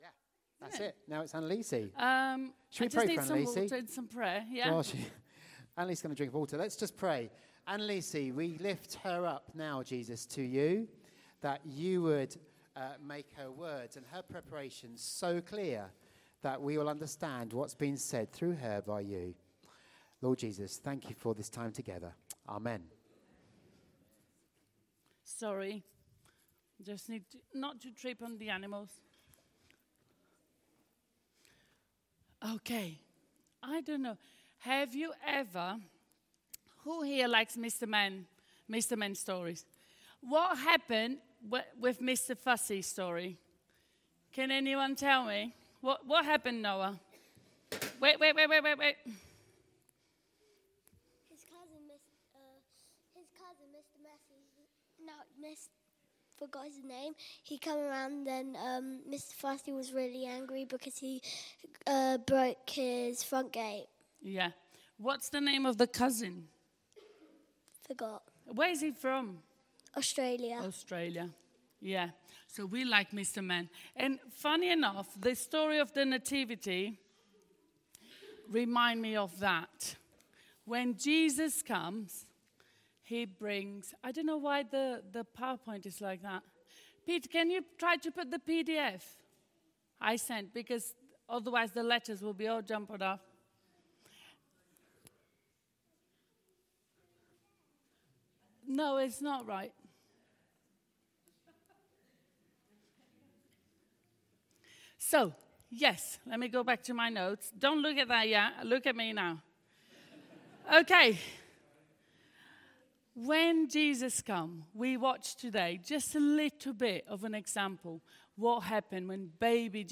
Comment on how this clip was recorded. Christmas Family Carol Service